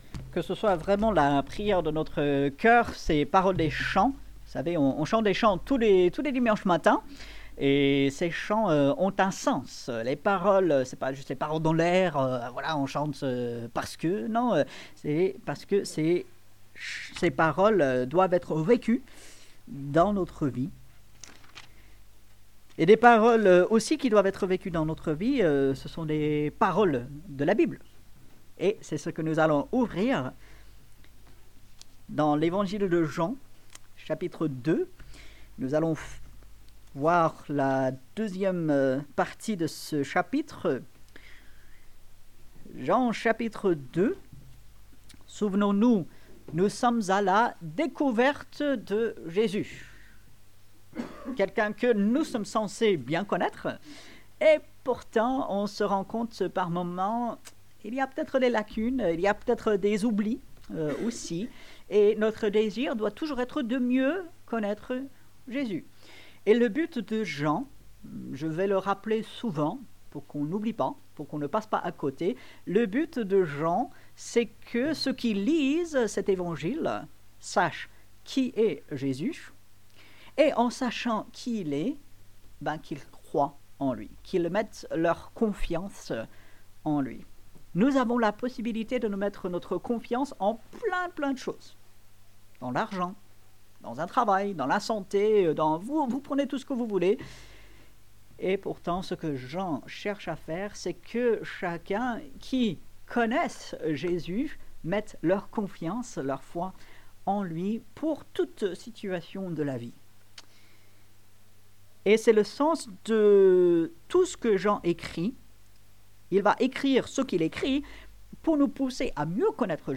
Ici nous vous proposons l'écoute des prédications qui sont apportées le dimanche matin.